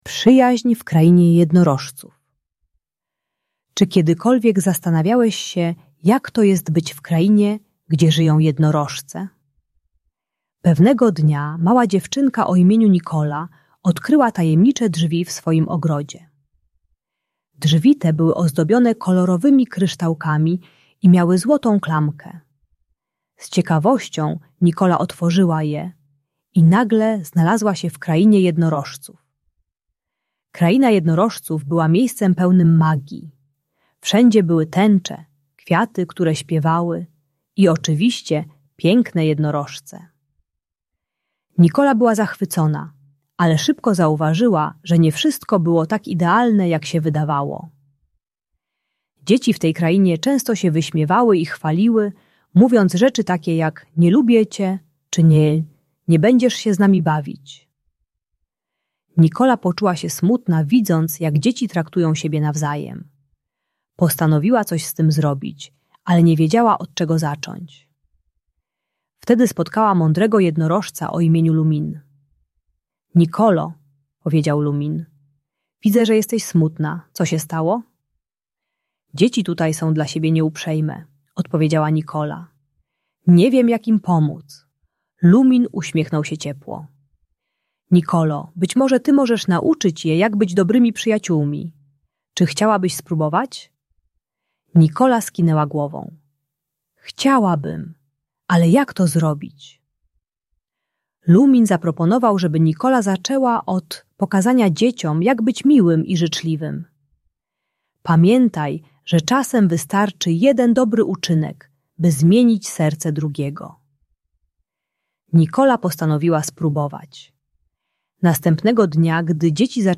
Przyjaźń w Krainie Jednorożców - Bunt i wybuchy złości | Audiobajka